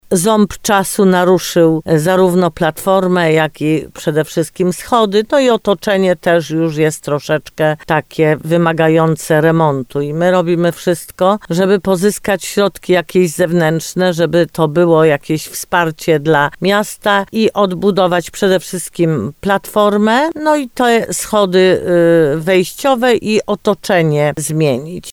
Chcemy przede wszystkim odbudować platformę, schody i zmienić otoczenie wokół krzyża – mówiła burmistrz Limanowej Jolanta Juszkiewicz.